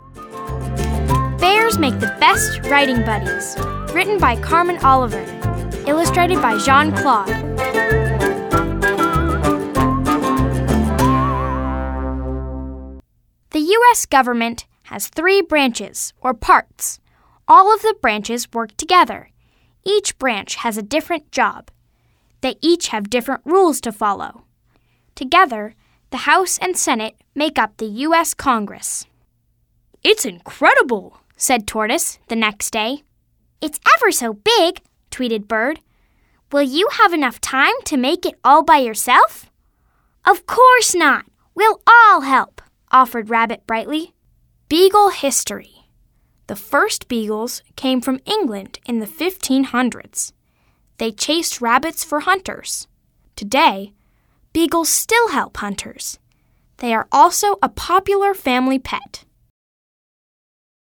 AUDIO BOOKS